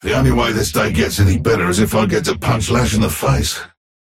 Bebop voice line - The only way this day gets any better is if I get to punch Lash in the face.